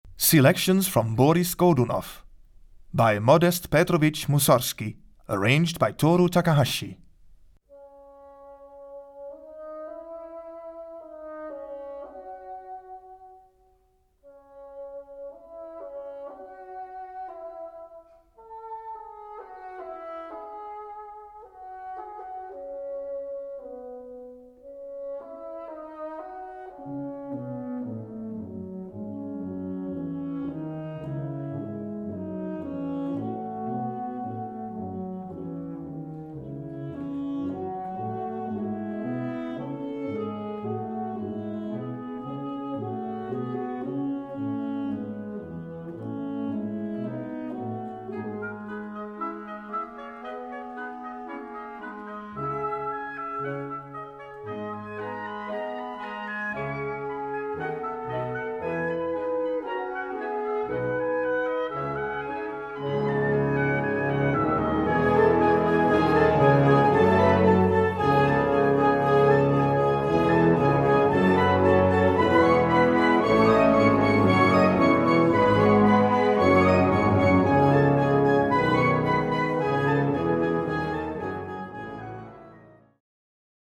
原調（c-sharp minor, C Major など）
重厚なサウンドで聞かせたいバンドに。